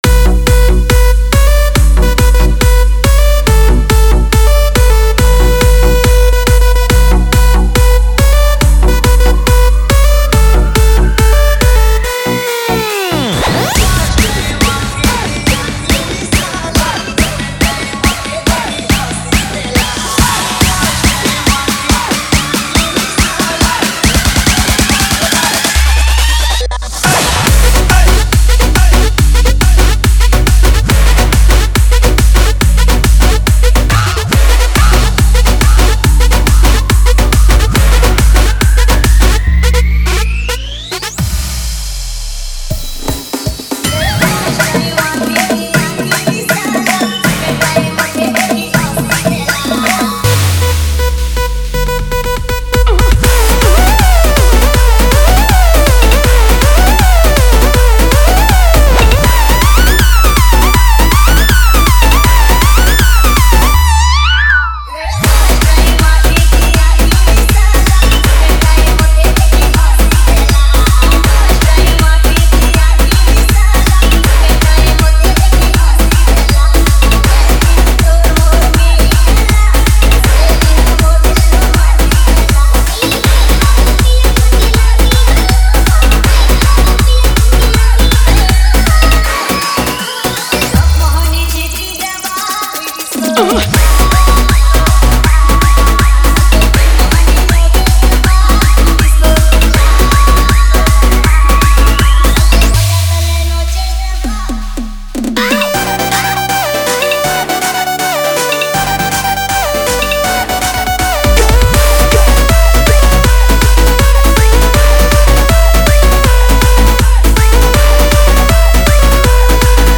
TRANCE DESI MIX